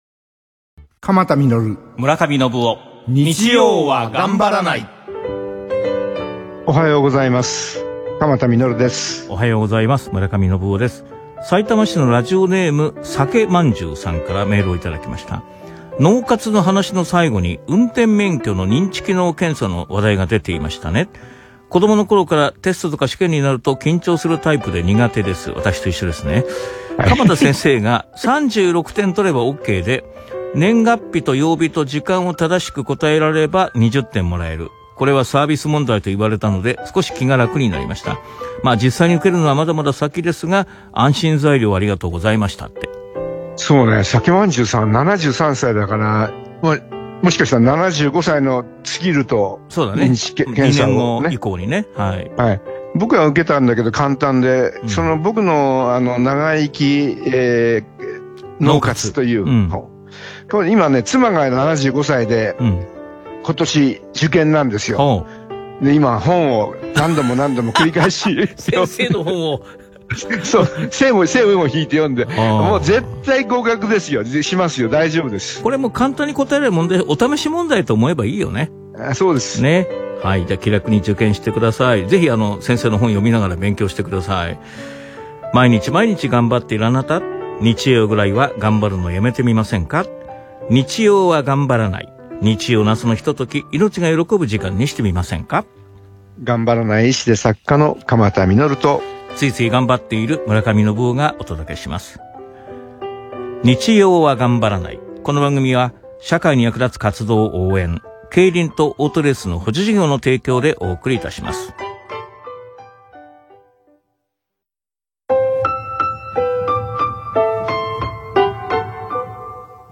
日曜日の早朝、6時20分からゆったりと放送されている番組があります。お医者さんで作家の鎌田實さんと元NHKアナウンサーの村上信夫さんが出演なさっています。
そこに、電話で出演する私の声が流れました。相変わらずの滑舌の悪さでモゴモゴ話していますが、ぜひ、お聴きください。